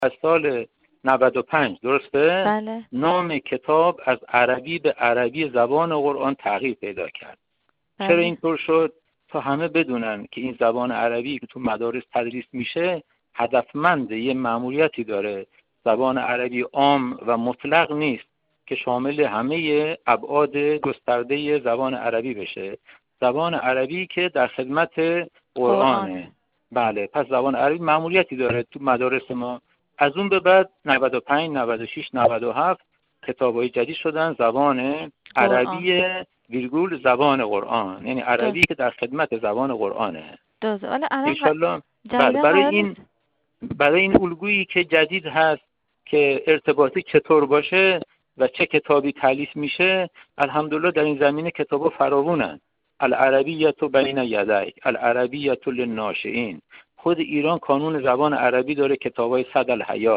ایکنا ـ در ابتدا درباره مأموریت آموزش و پرورش درباره آموزش زبان عربی توضیح دهید.